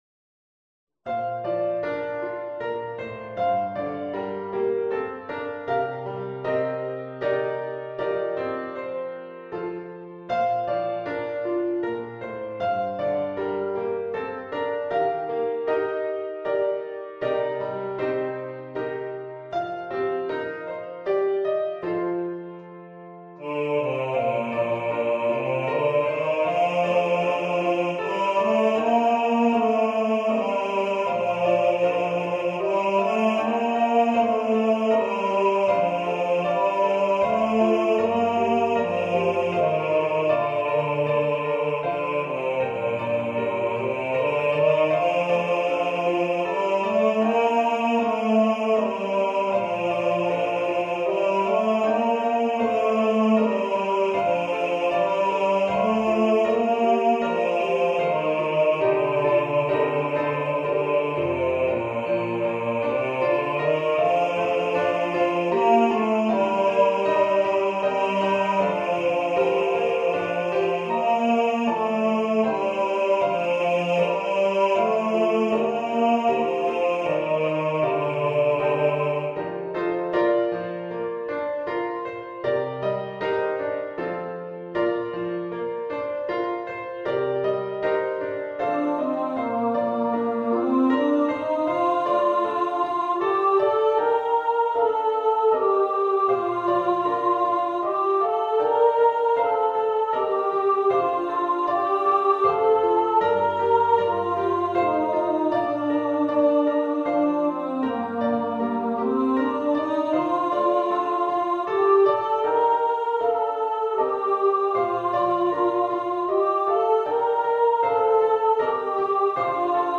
engl. Weihnachtslied